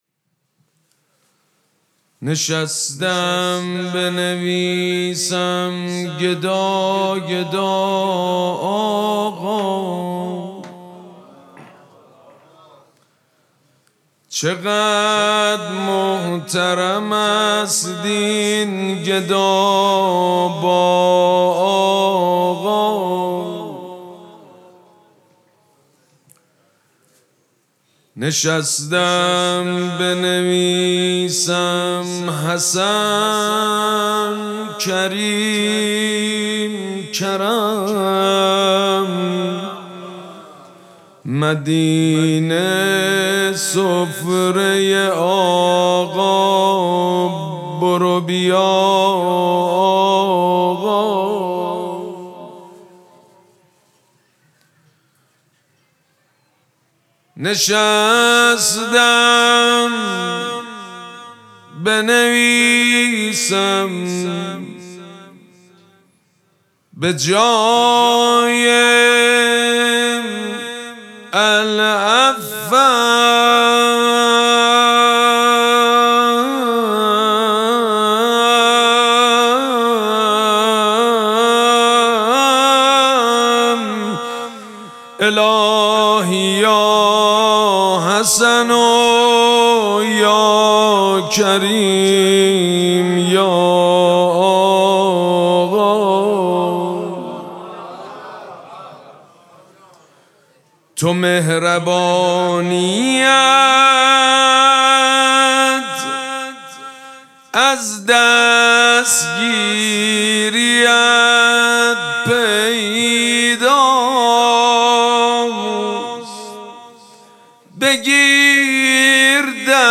مراسم جشن شب ولادت امام حسن مجتبی(ع) شنبه ۲۵ اسفند ماه ۱۴۰۳ | ۱۴ رمضان ۱۴۴۶ حسینیه ریحانه الحسین سلام الله علیها
شعر خوانی مداح حاج سید مجید بنی فاطمه